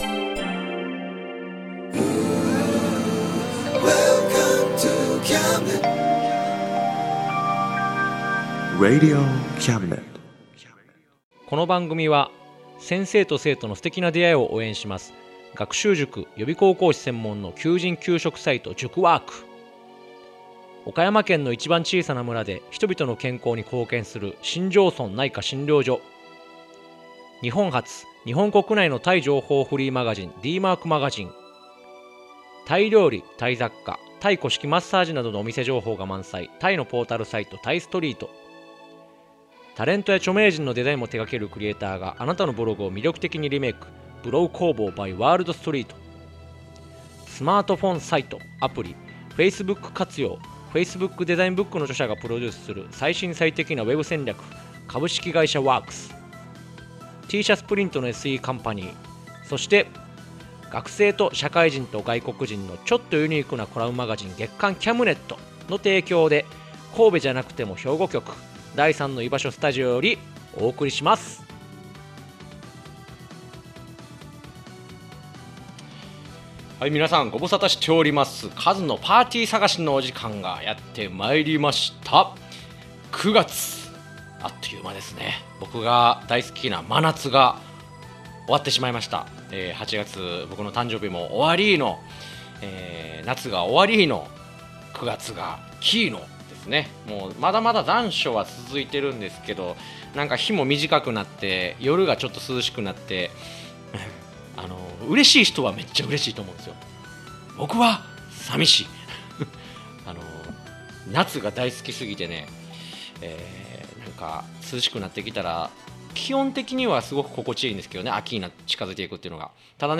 ●ほんの数十秒のフォロバって...絶対に怒られてるやん www ●依頼があった２チーム目のよさこい演舞曲、ついに解禁 ●皆既月食を観てる間にできた新曲を生歌で披露 ●11月開催、山口岩男さんとの「Voices -よくぞご無事で-」in 関西 決定 !!